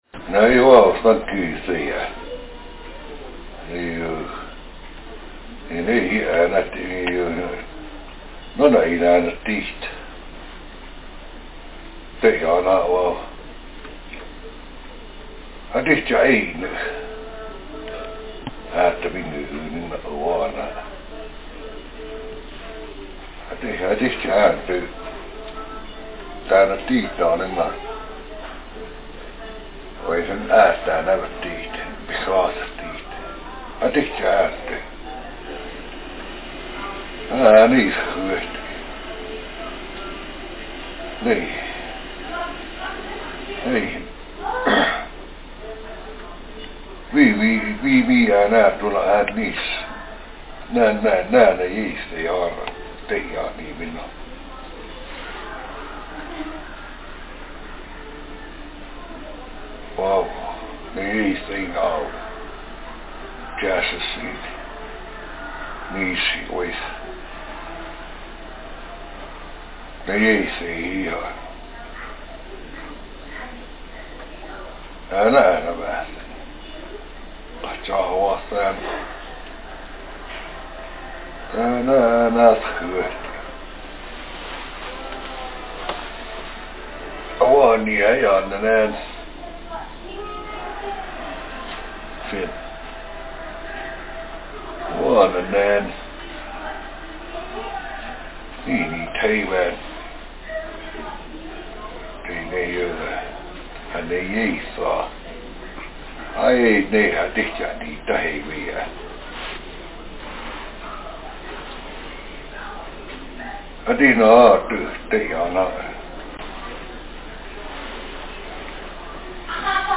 Speaker sexm
Text genrepersonal narrative